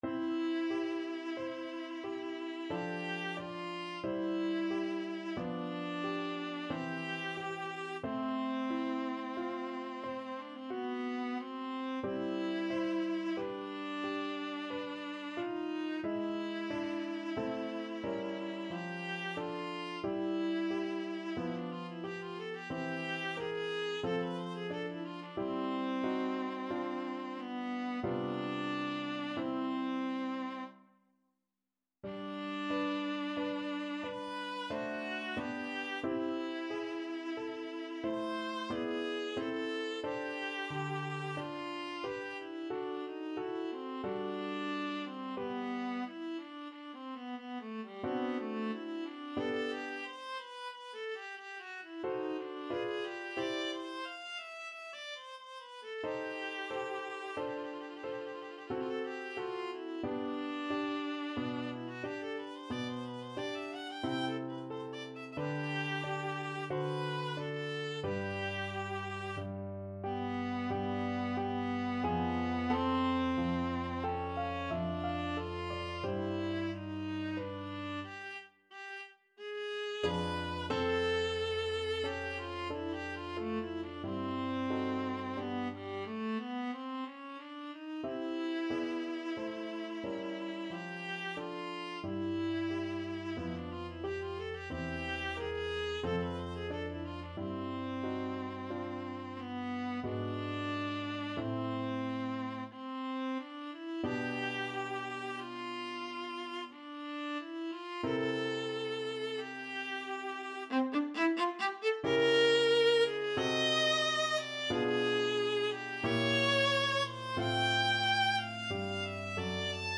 Viola
Adagio =45
C major (Sounding Pitch) (View more C major Music for Viola )
3/4 (View more 3/4 Music)
Classical (View more Classical Viola Music)
spohr_concerto_1_op26_2nd_mvt_VLA.mp3